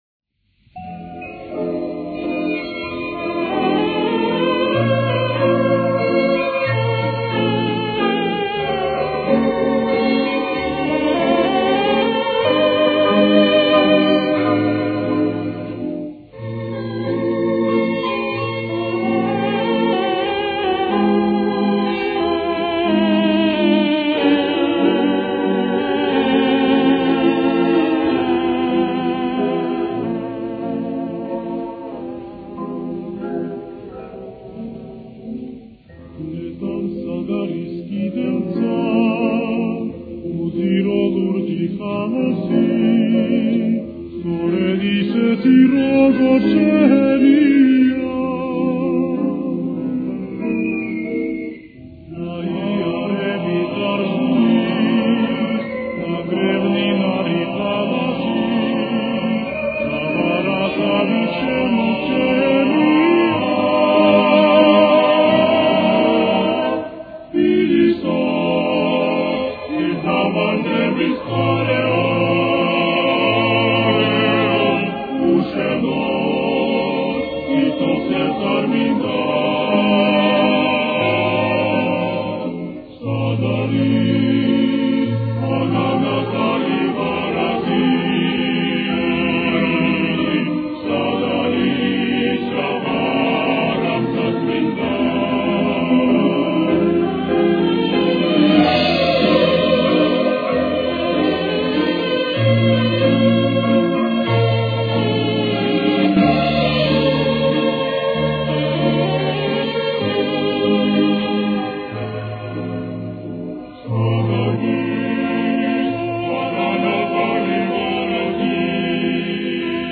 с очень низким качеством (16 – 32 кБит/с).
Тональность: Фа минор. Темп: 95.